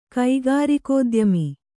♪ kaigārikōdyami